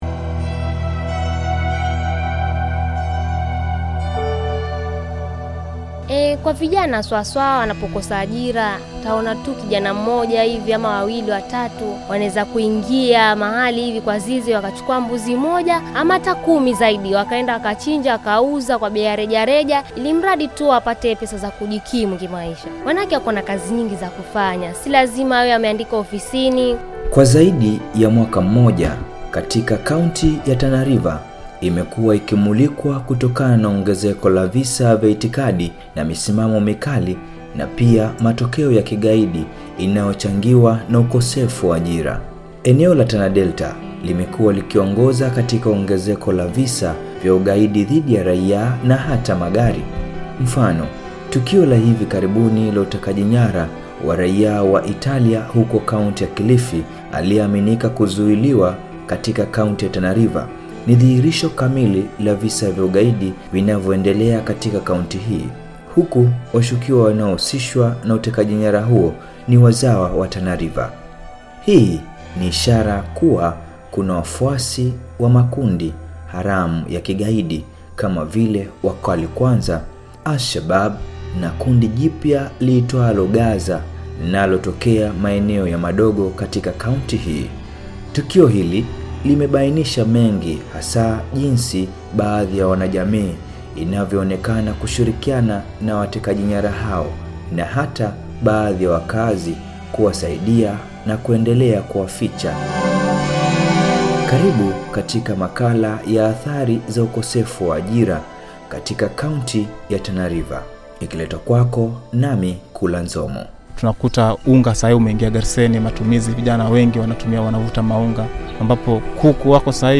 CVE-feature.-Amani-FM.-Tana-River.mp3